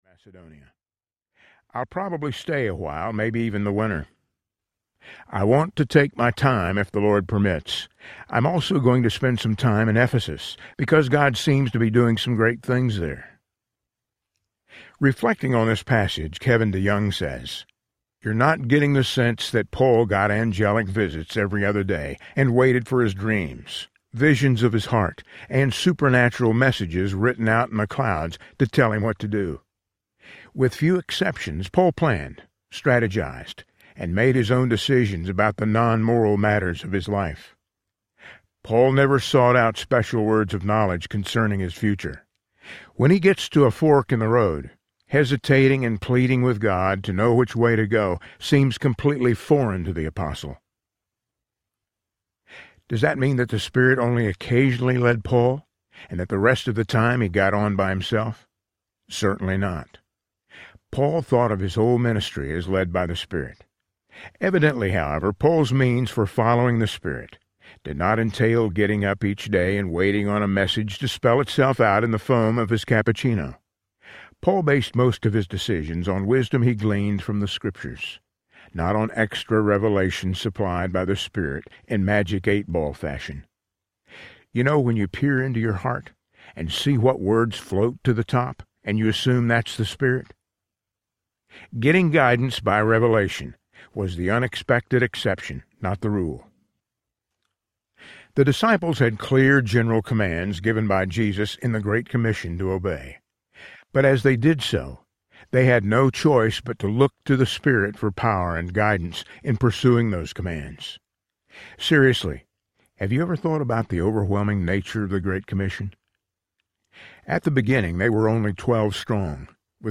Jesus, Continued Audiobook
Narrator
8.2 Hrs. – Unabridged